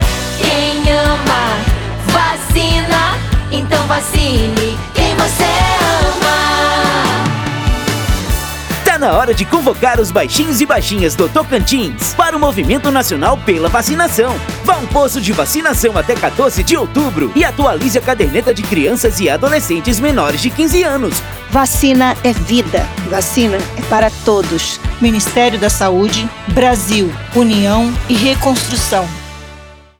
Áudio - Spot 30seg - Campanha de Multivacinação no Tocantins - 1,1mb .mp3